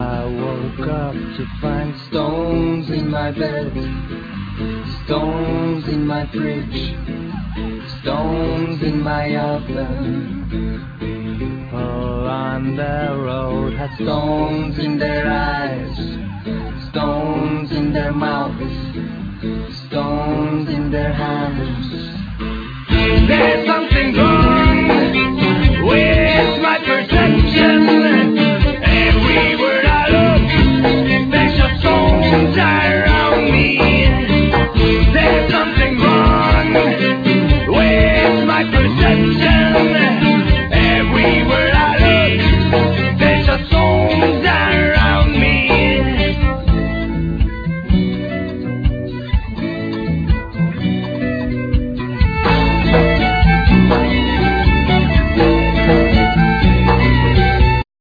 Vocals,Violin,Guitar,Bass
Guitars,Bass,Vocals
Cello,Vocals
Drums,Percussions